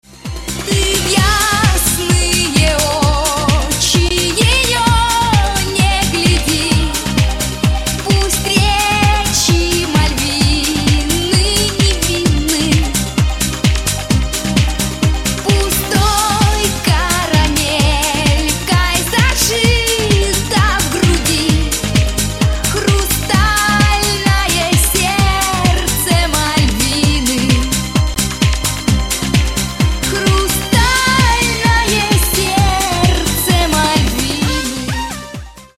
Рингтоны Дискотека 90х
Поп Рингтоны